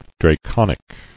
[dra·con·ic]